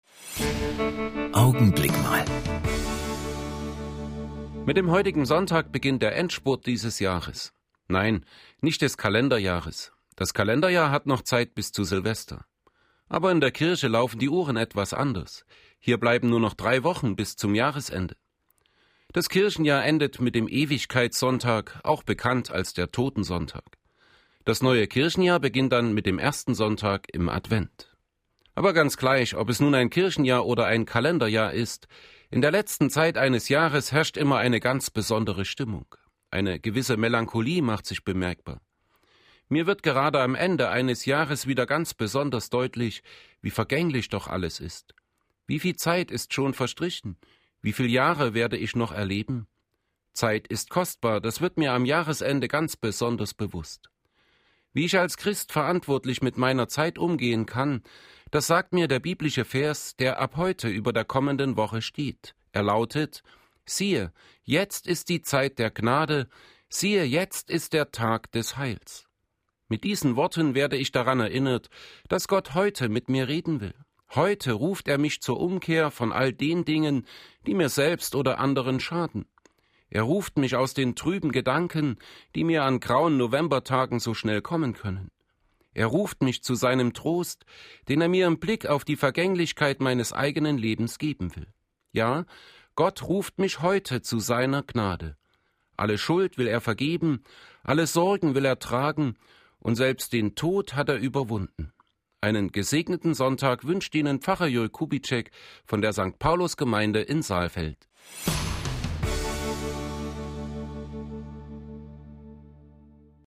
Radioandachten Gottesdienst